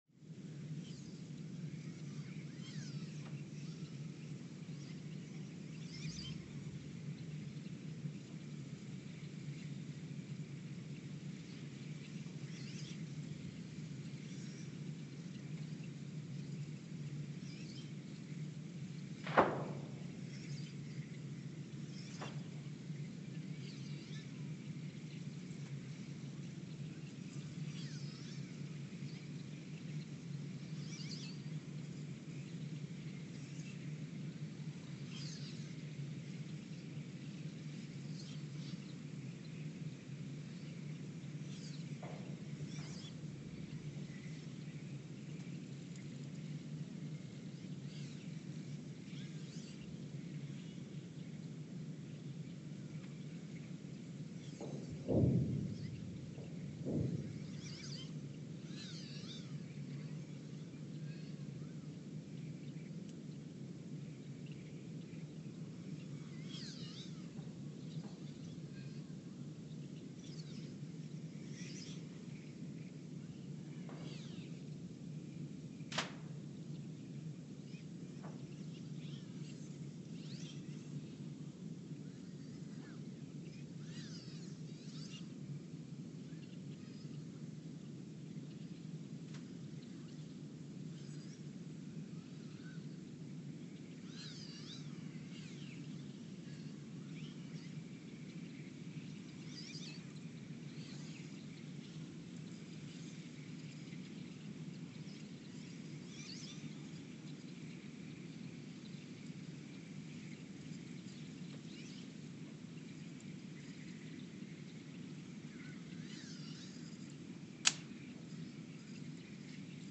The Earthsound Project is an ongoing audio and conceptual experiment to bring the deep seismic and atmospheric sounds of the planet into conscious awareness.
Station : ULN (network: IRIS/USGS ) at Ulaanbaatar, Mongolia Sensor : STS-1V/VBB
Speedup : ×900 (transposed up about 10 octaves)
Loop duration (audio) : 11:12 (stereo)